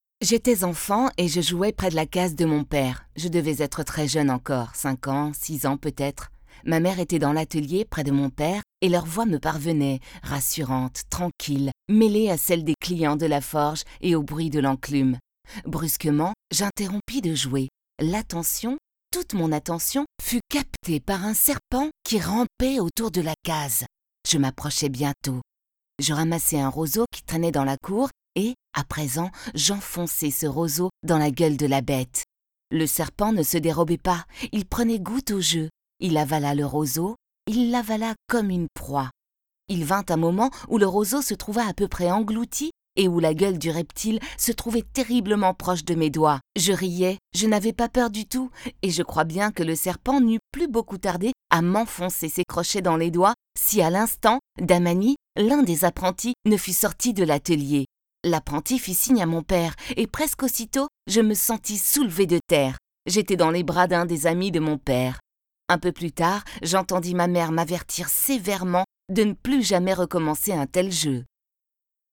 Voice over depuis 1988, je peux aussi bien enregistrer des messages sur un ton jeune et dynamique que grave et posé.
Kein Dialekt
Sprechprobe: Sonstiges (Muttersprache):